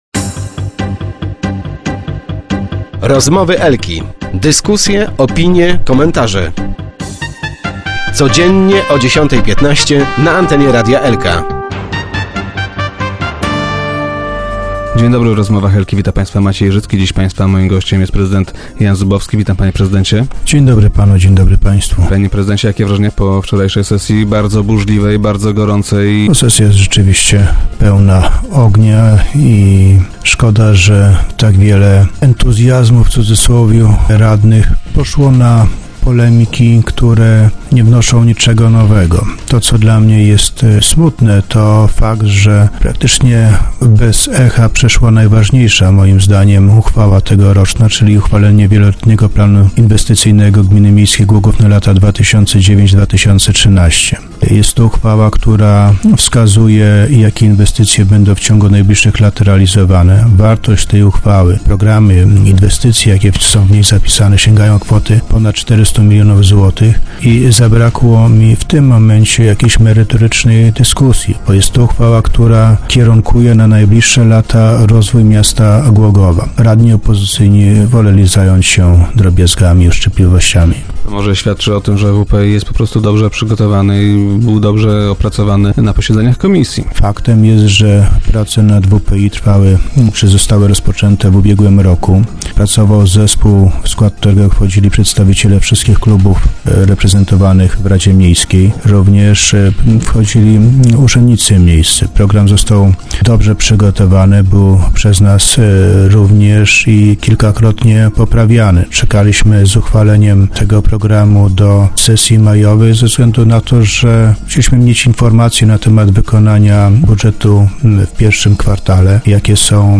- Szkoda, że tak wiele "entuzjazmu" radnych poszło na polemiki, które nie wnoszą niczego nowego. To co dla mnie jest smutne to fakt, że praktycznie bez echa przeszłą najważniejsza moim zdaniem tegoroczna uchwała, czyli Wieloletni Plan Inwestycyjny na lata 2009 - 2013. Jest to uchwała, która wskazuje, jakie inwestycje będą w ciągu najbliższych lat realizowane. Wartość tych inwestycji sięgnęła kwoty ponad 400 milionów złotych i w tym momencie zabrakło mi merytorycznej dyskusji. Radni opozycyjni woleli zająć się drobiazgami i uszczypliwościami - powiedział na radiowej antenie prezydent Zubowski.